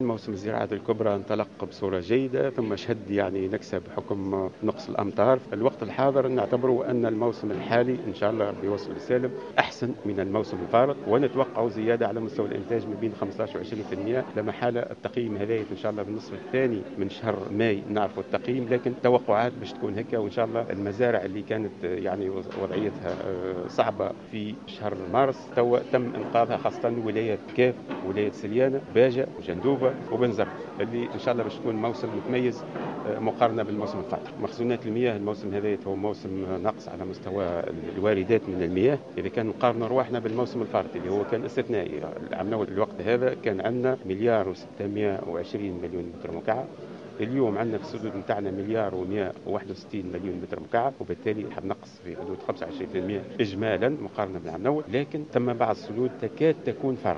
وأكد على هامش ندوة صحفية في العاصمة تونس أن الموسم الحالي سيكون أفضل من السابق بعد ان تم انقاذ المزارع الكبرى في الكاف وجندوبة وباجة وبنزرت وأضاف أن التقييم النهائي سيتم بعد منتصف الشهر الحالي.